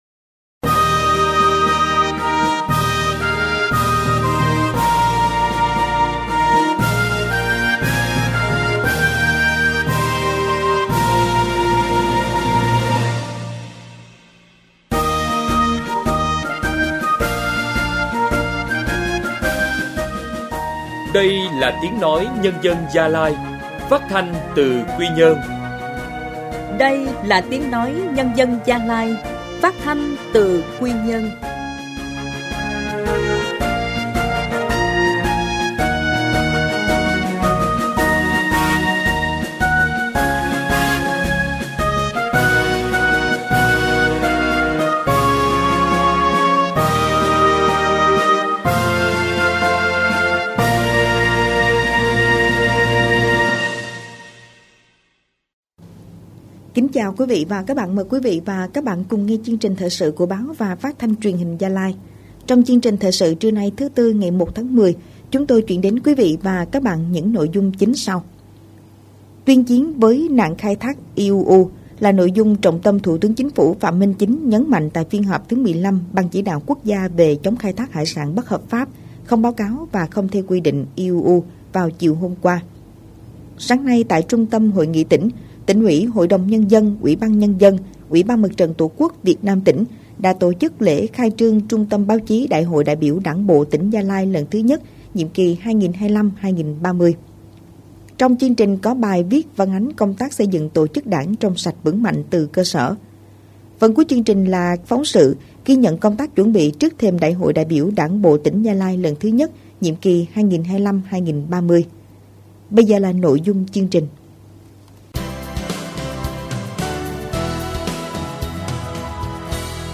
Thời sự phát thanh